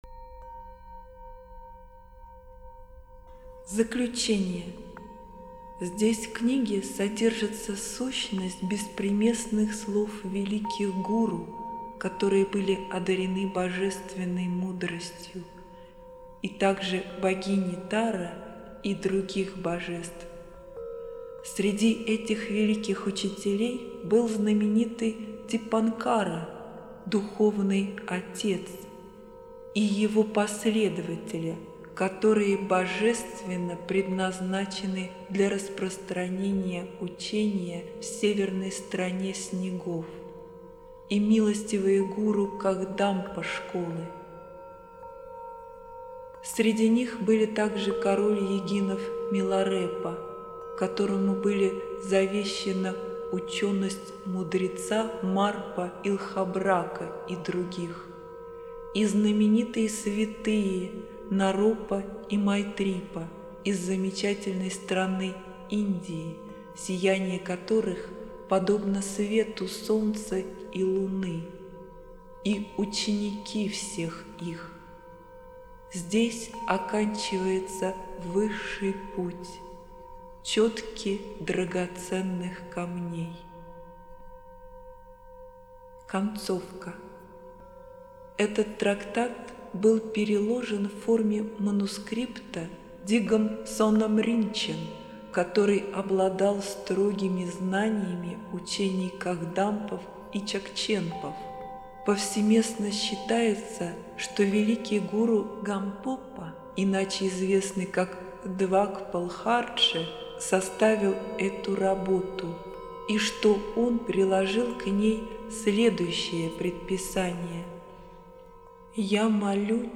Аудио-книга "Драгоценные чётки"
Восприятие текста улучшает специально подобранная тибетская музыка.